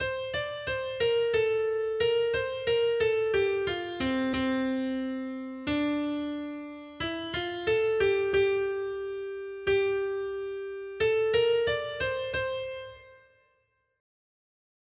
responsoriální žalm